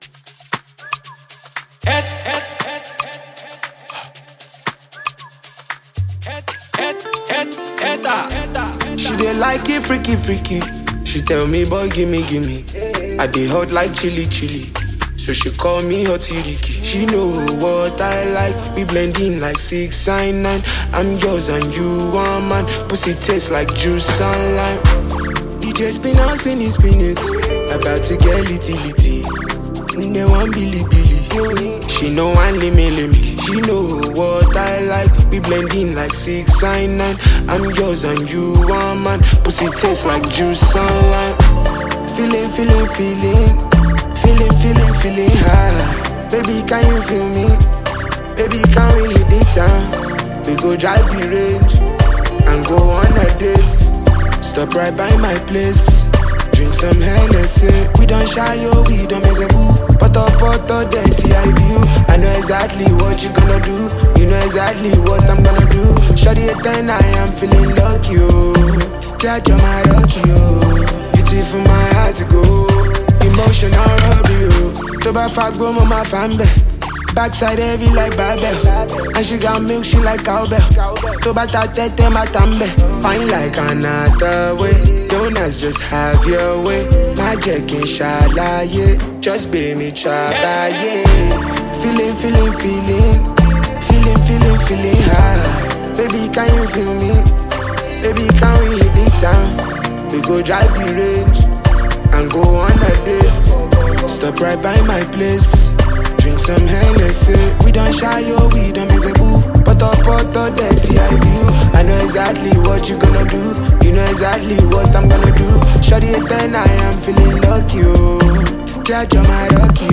gbedu